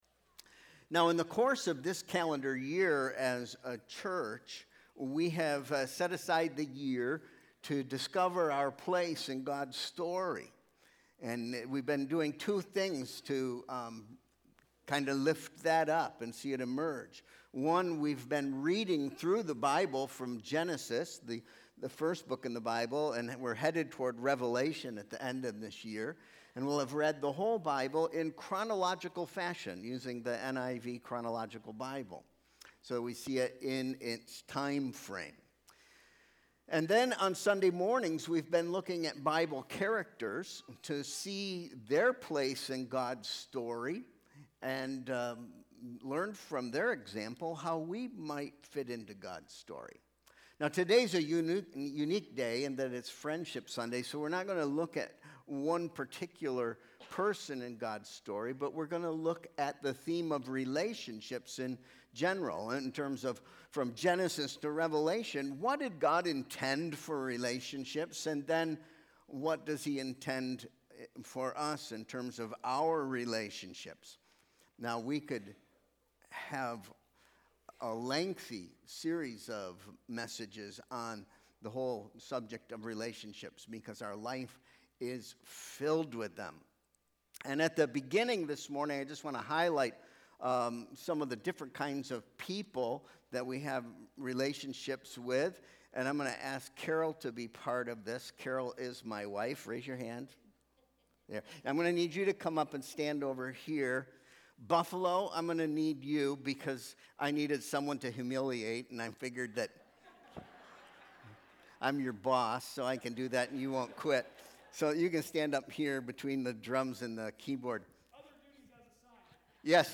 Sermon Proposition: God has placed people in your life for your good.